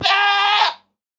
Minecraft Version Minecraft Version latest Latest Release | Latest Snapshot latest / assets / minecraft / sounds / mob / goat / screaming_hurt2.ogg Compare With Compare With Latest Release | Latest Snapshot
screaming_hurt2.ogg